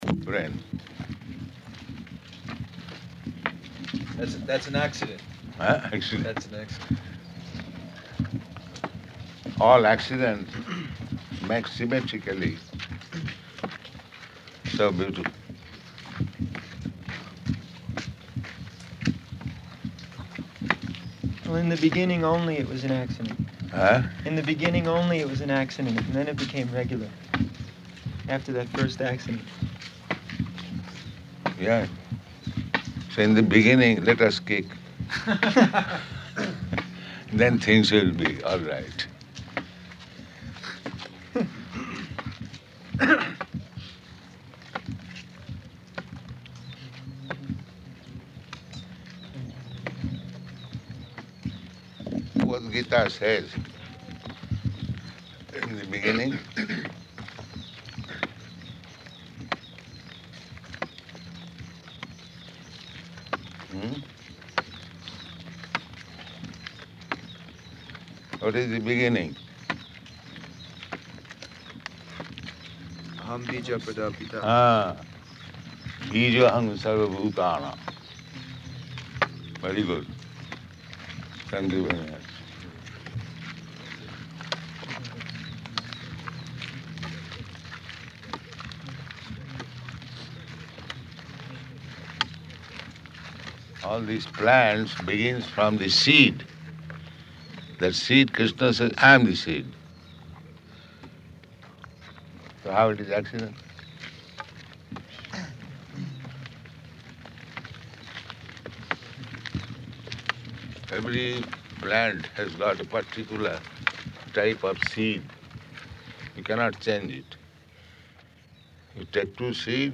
-- Type: Walk Dated: November 2nd 1975 Location: Nairobi Audio file